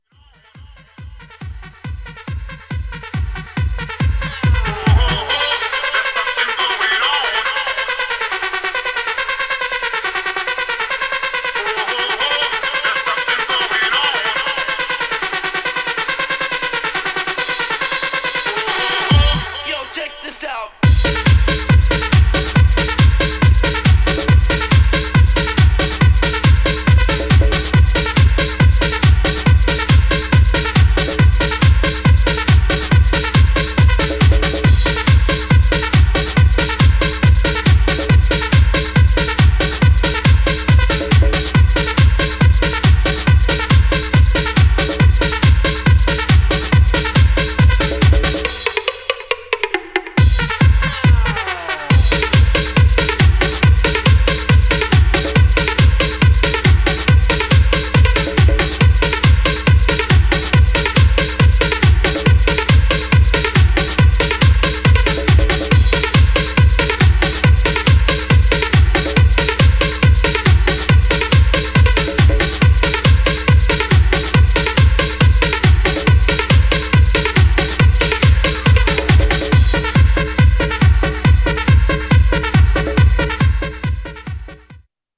Styl: Disco, Progressive, House, Trance